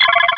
Cri de Charmillon dans Pokémon Rubis et Saphir.